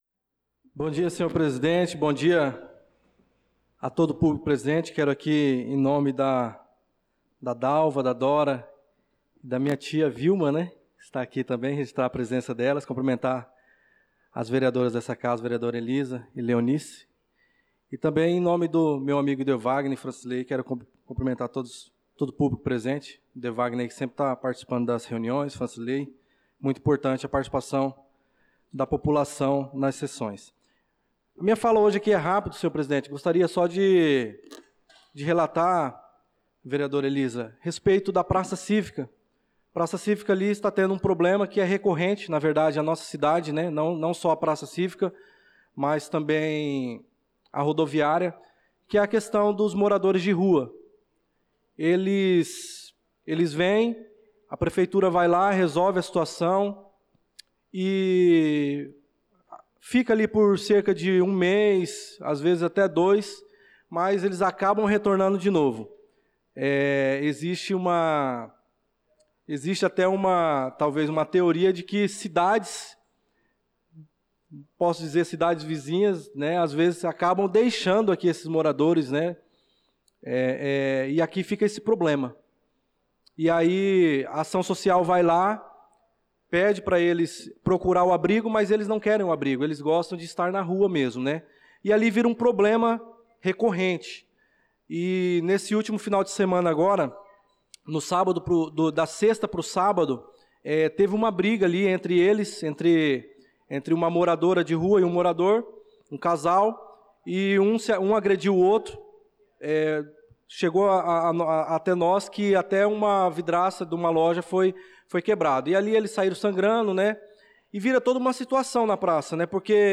Pronunciamento do vereador Darlan Carvalho na Sessão Ordinária do dia 26/05/2025